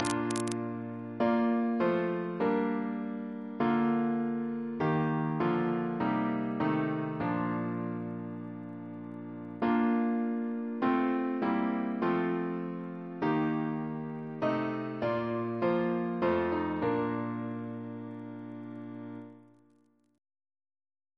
Double chant in B♭ Composer: Henry Lawes (1595-1662) Reference psalters: ACB: 13; CWP: 187; H1940: 646; H1982: S246; OCB: 10; PP/SNCB: 118; RSCM: 87